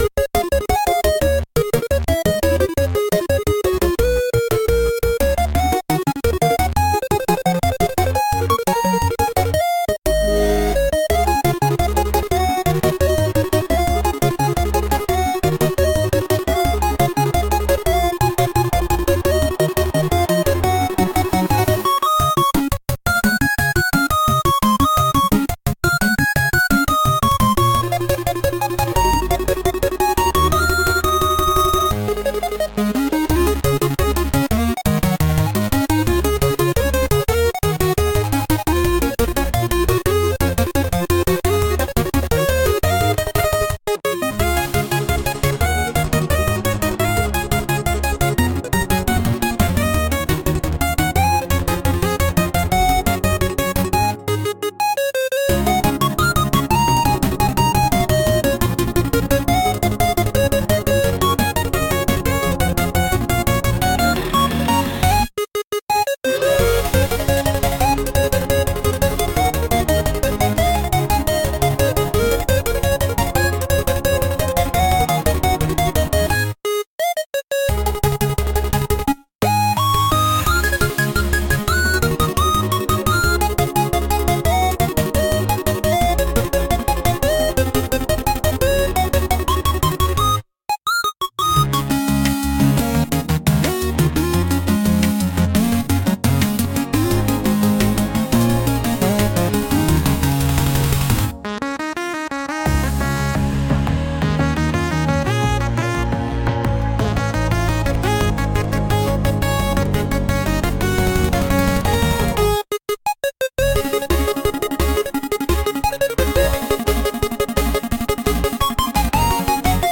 好きな人のところへ駆け付けたいようなピコピコ8bitサウンドです。